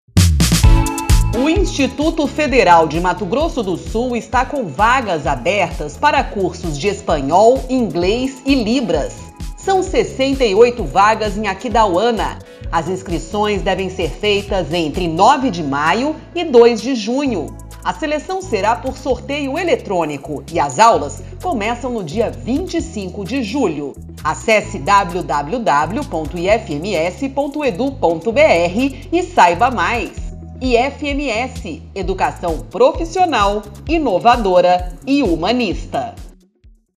Spot - Cursos de idiomas para o 2º semestre de 2022 em Aquidauana
Áudio enviado às rádios para divulgação institucional do IFMS.